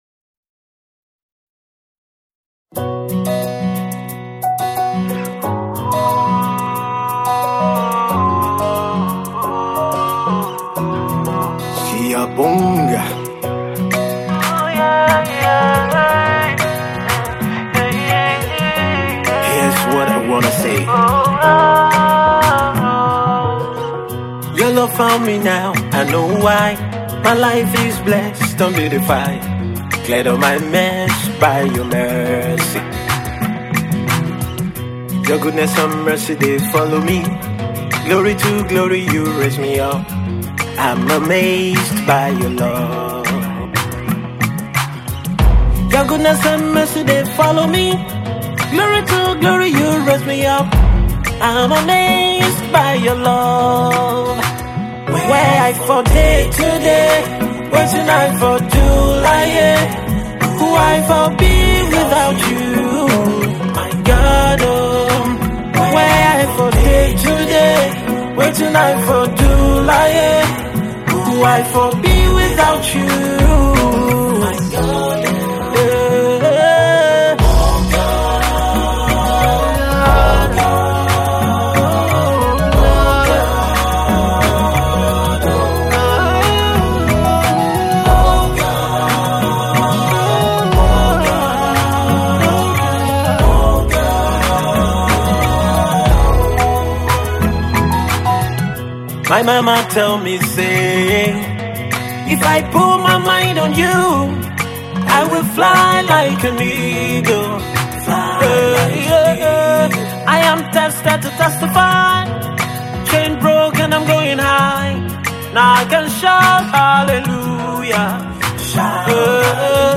This mid-tempo song is a mix of soft rock with African beats
gospel music